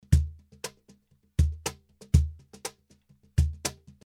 Звук кахона
Друзья, кто часто работает с этим инструментом, не резковато ли записал?